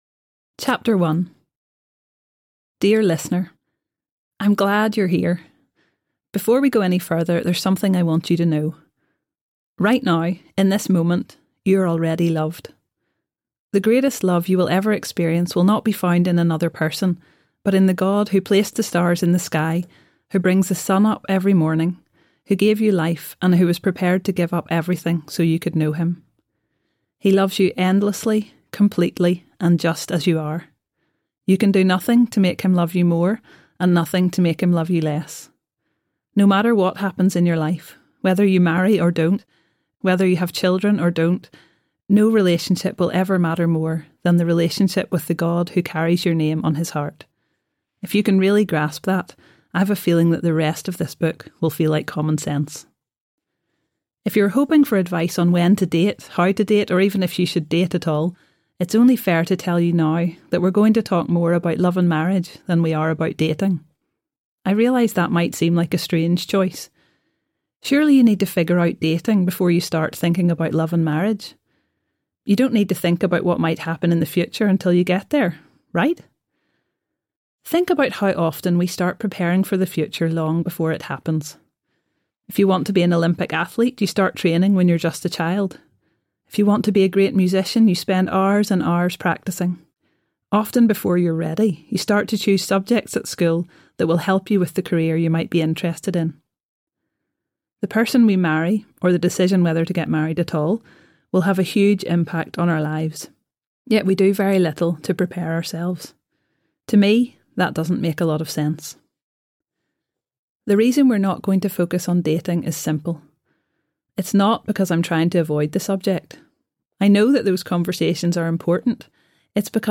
Choosing Love in a Broken World Audiobook